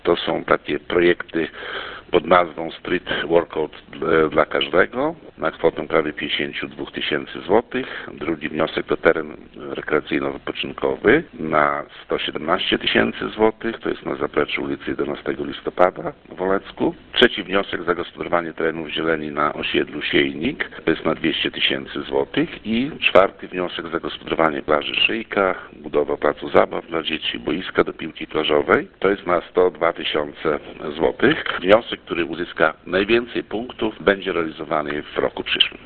O tym Wacław Olszewski, burmistrz Olecka.